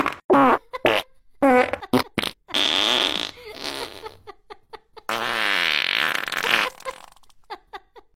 পাদের শব্দ sound effects free download